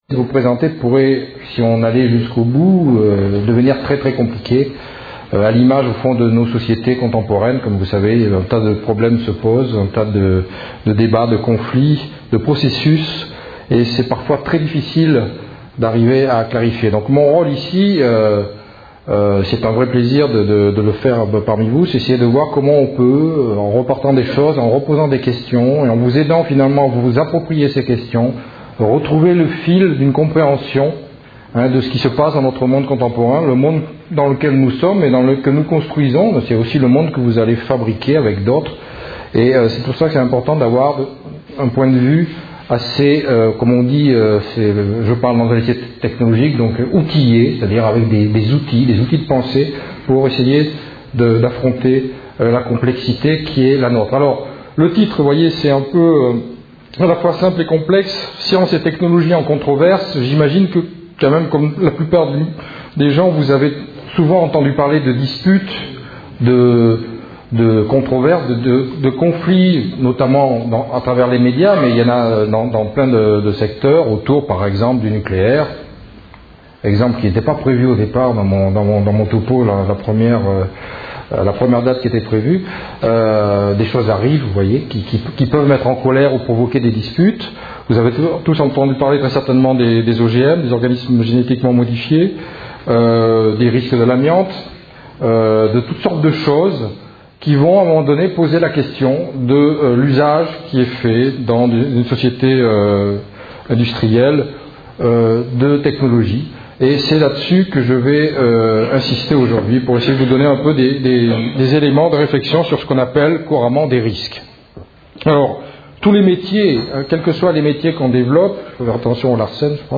Une conférence de l'UTLS au Lycée Les technologies et leurs risques